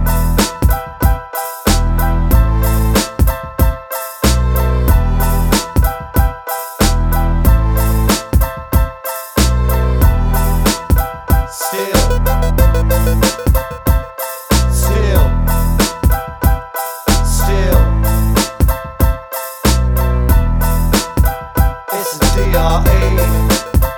no Backing Vocals Dance 4:20 Buy £1.50